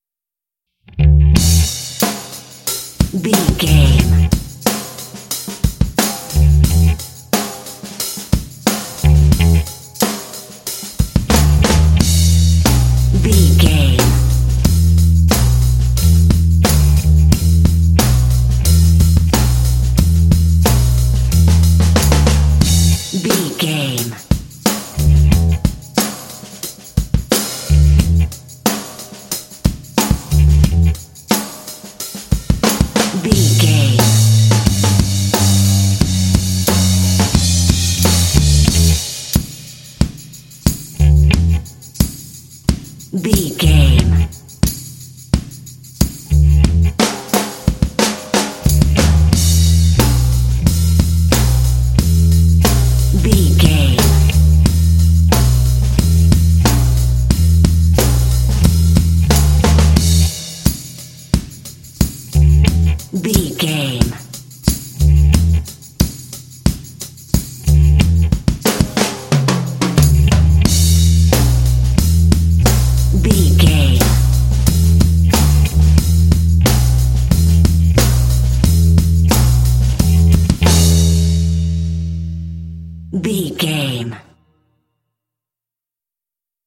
Uplifting
Mixolydian
playful
cheerful/happy
drums
bass guitar
country
bluegrass